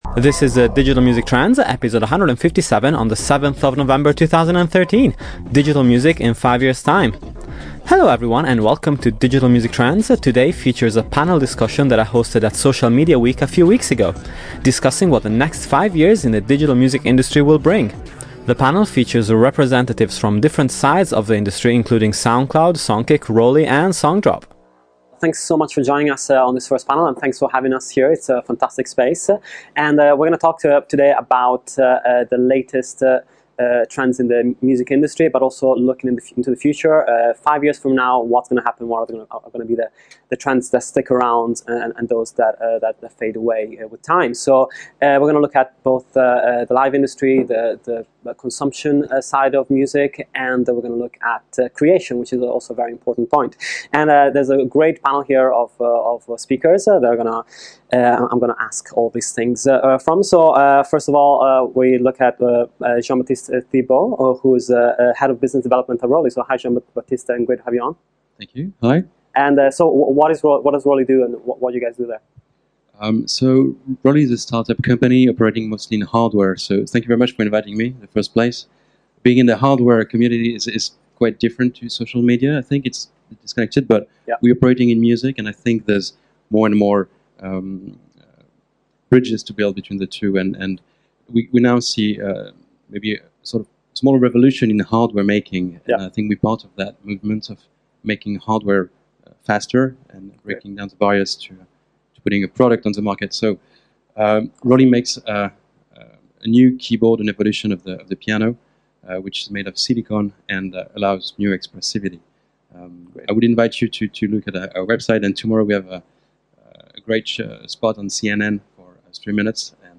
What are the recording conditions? a panel I moderated at Social Media Week a few weeks back